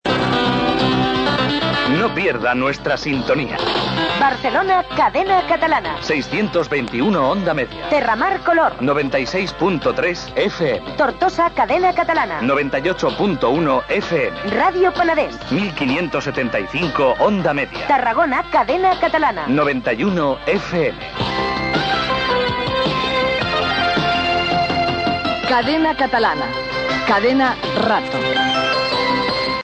bba054f9cd92ceae0aba7aa625f3d09508c9a791.mp3 Títol Cadena Catalana Emissora Cadena Catalana Barcelona Cadena Cadena Catalana Cadena Rato Titularitat Privada estatal Descripció Llistat de freqüències d'emissió de Cadena Catalana i identificació.